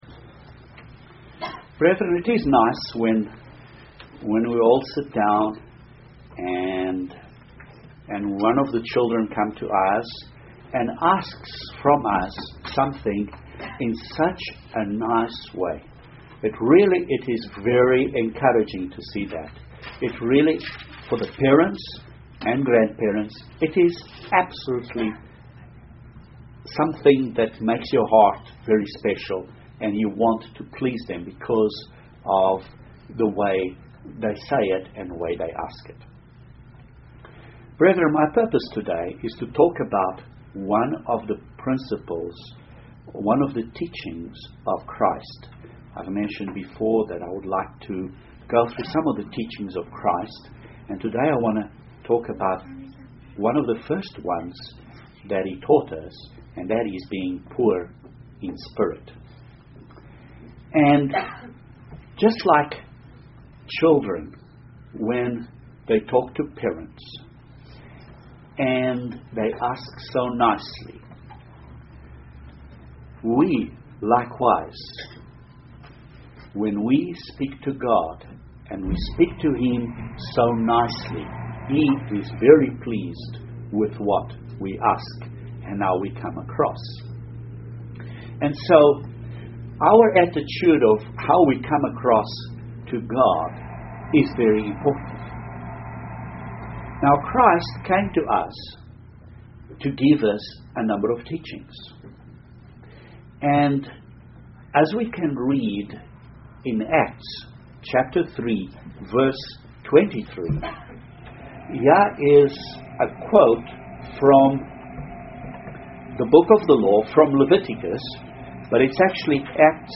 What does poor in spirit mean? This sermon goes into a number of points about being poor in spirit.